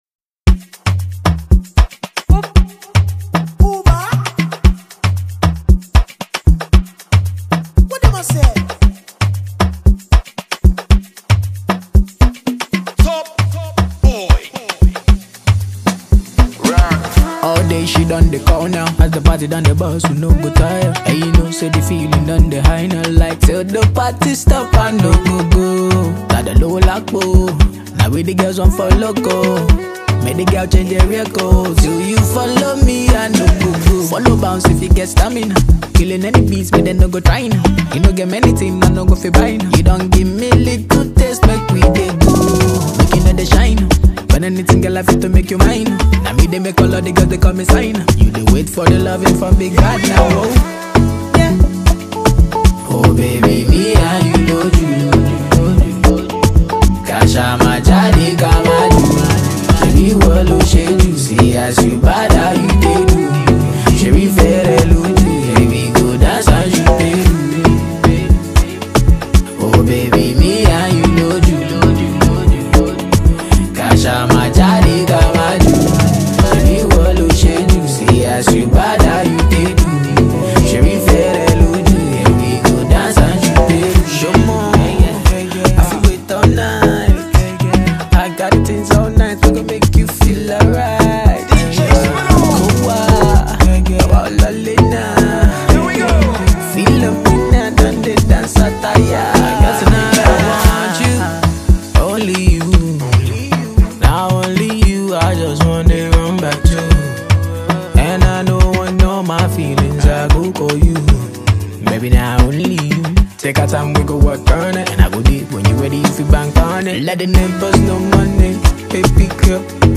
a sound that was both robust and innovative